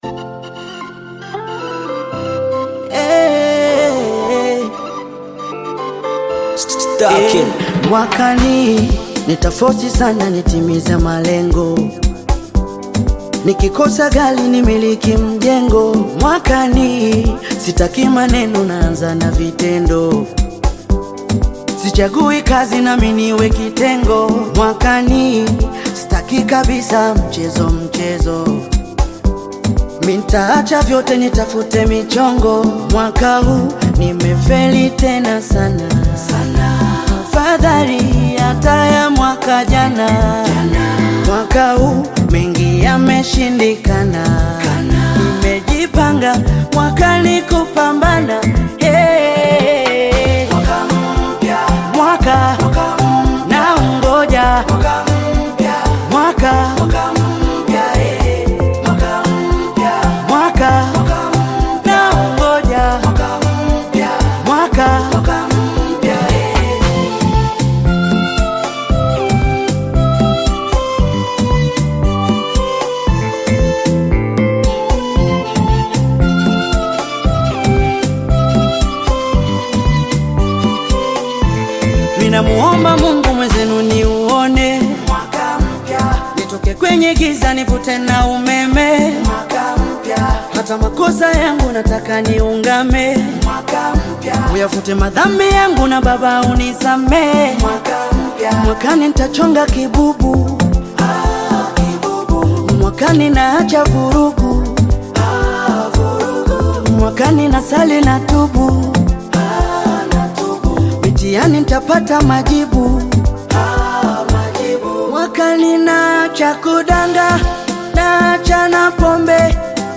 Bongo Flava